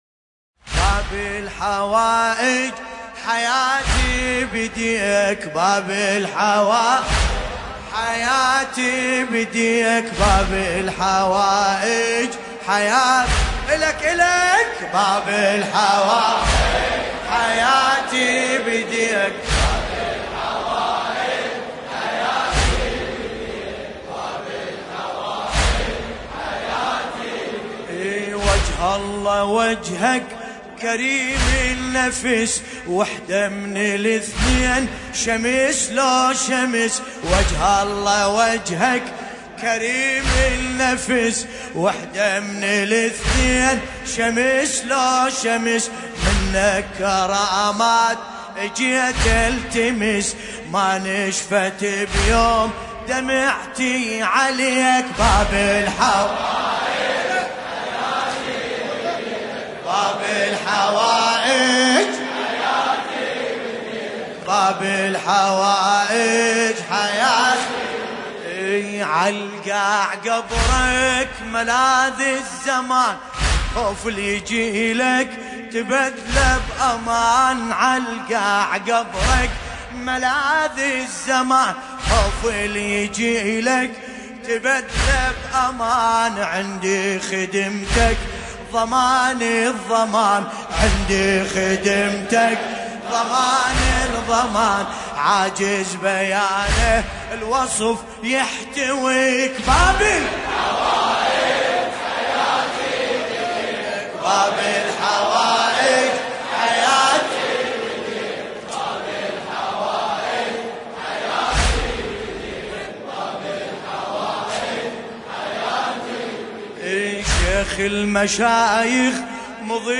قصيدة
المناسبة : استشهاد الامام الجواد (ع)
طور : يا_ابوطالب
جامع الحاج علي باش بغداد الكريعات